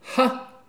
ajout des sons enregistrés à l'afk ... Les sons ont été découpés en morceaux exploitables. 2017-04-10 17:58:57 +02:00 120 KiB Raw History